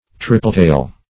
Triple-tail \Tri"ple-tail`\, n. (Zool.)